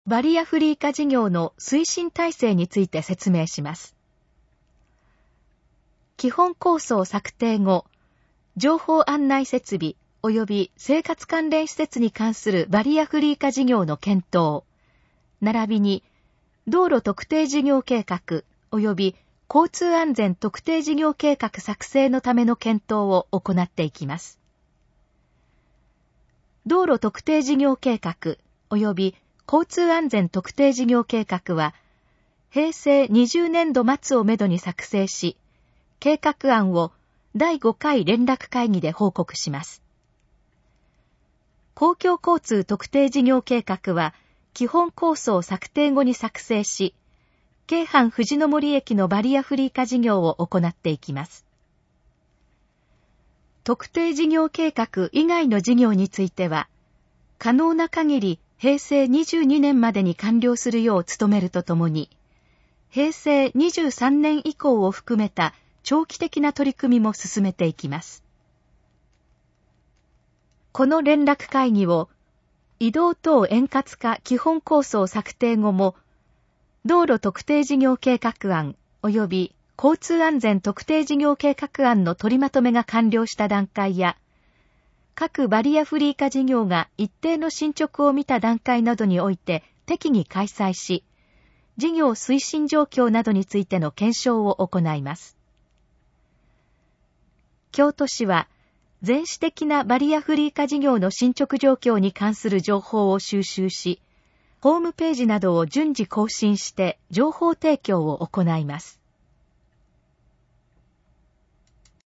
このページの要約を音声で読み上げます。
ナレーション再生 約438KB